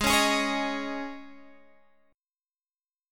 Absus4 chord